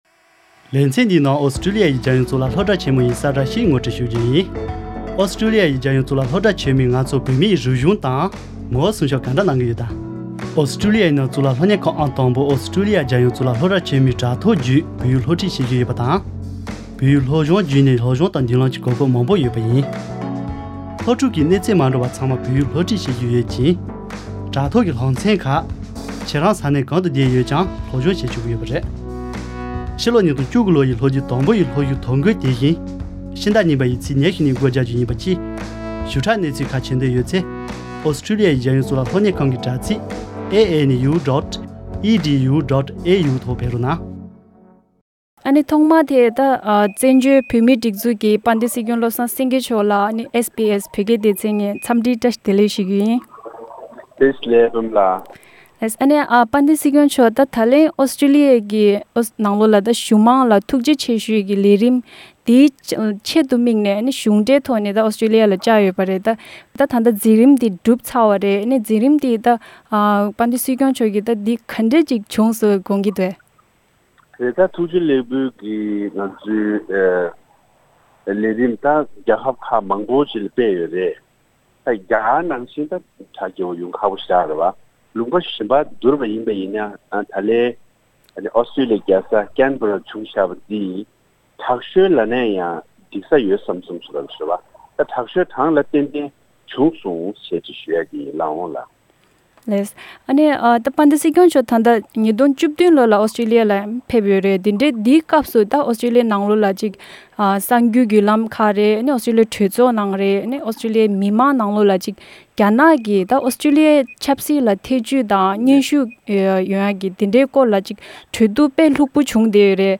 Exclusive interview with Sikyong Lobsang Sangay